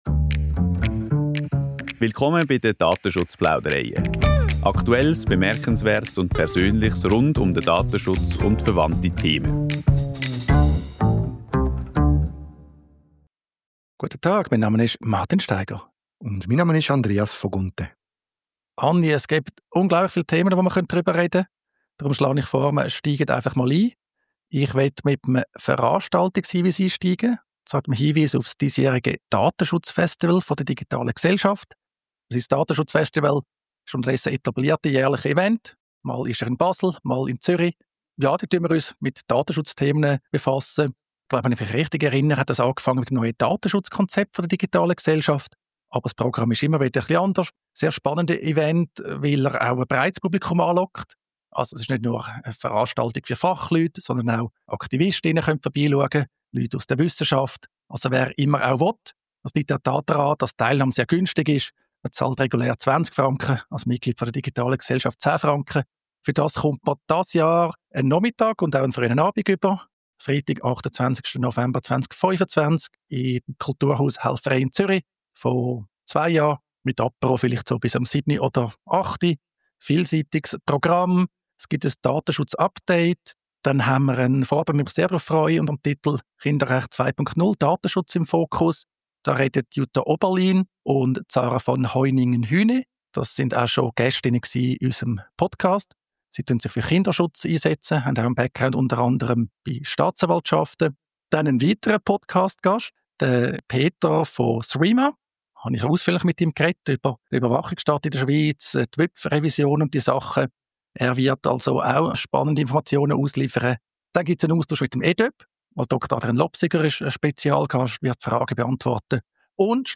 Danach wird das Gespräch «rantig»: